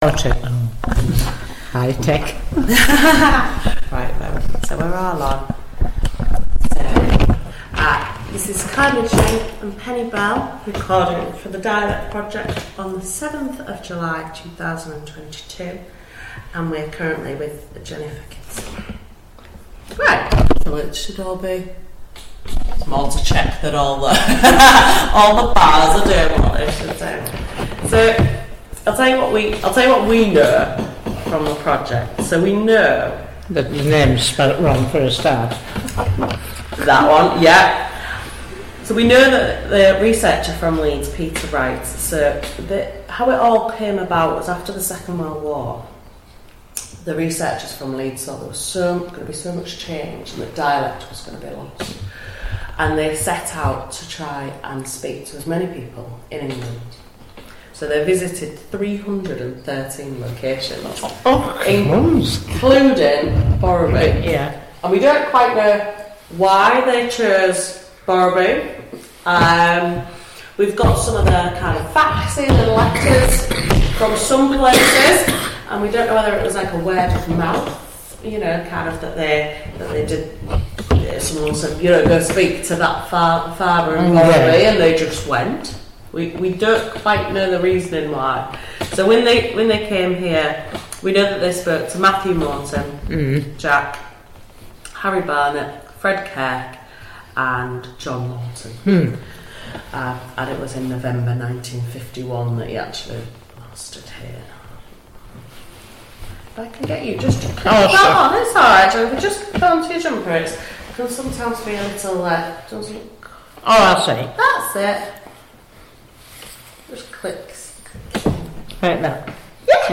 Digital recording of oral history interview conducted with named interviewee (see item title) as part of National Lottery Heritage funded, "Dialect and Heritage" Project.